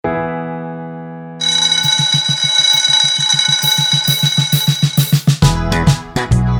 With Intro Count